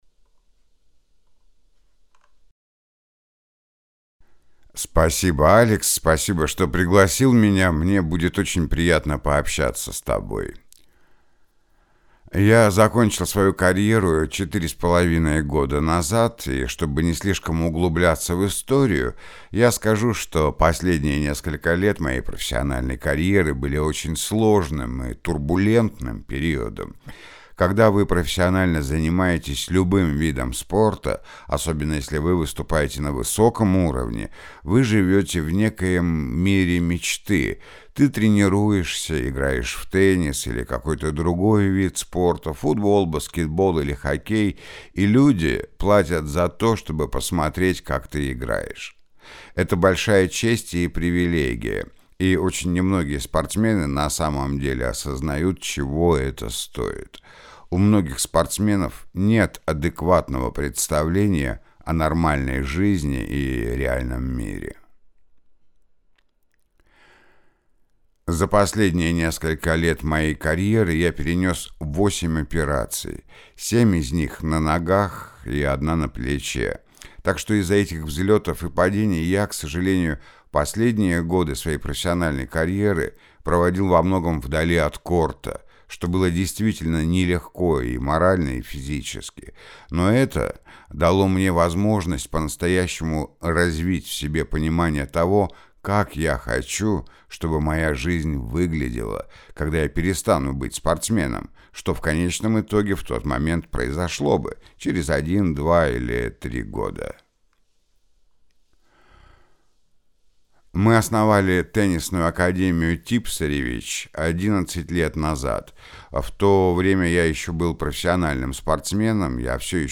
теннис-интервью-большой шлем
Муж, Закадровый текст/Зрелый